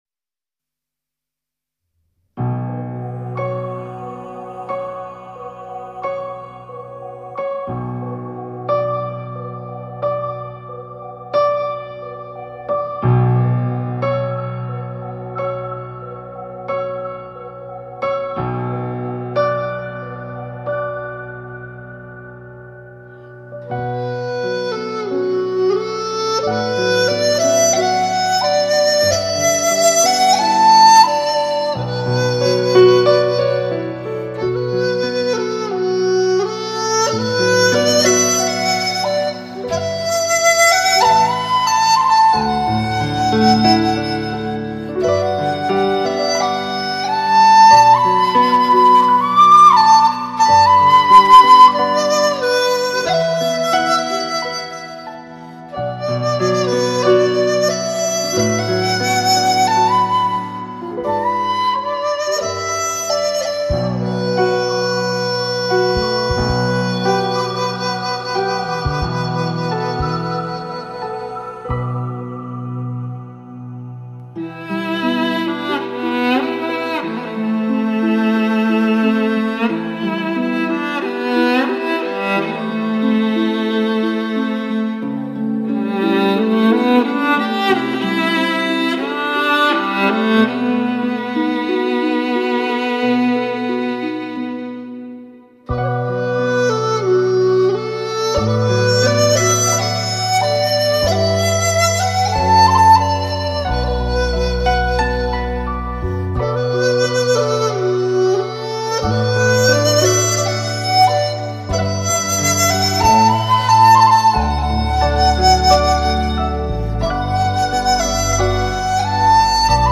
音乐气势超级宏大，乐器音色超级靓绝，典范级的录音制作精彩绝伦。